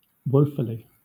Ääntäminen
Vaihtoehtoiset kirjoitusmuodot (vanhahtava) wofully Synonyymit dolefully Ääntäminen Southern England Haettu sana löytyi näillä lähdekielillä: englanti Käännöksiä ei löytynyt valitulle kohdekielelle.